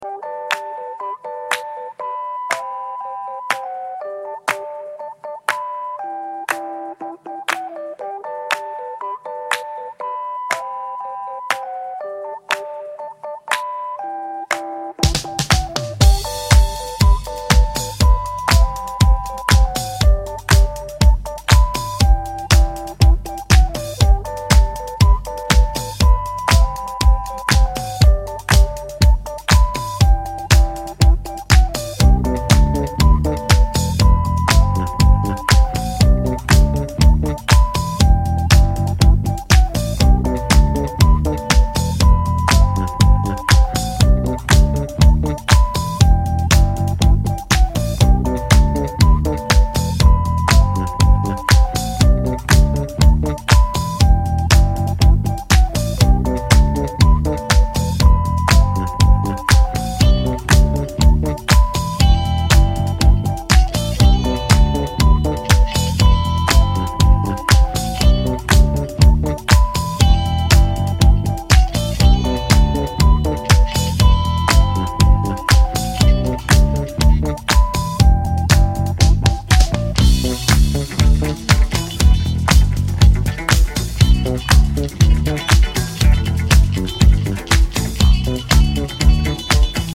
Re-press of this absolute disco classic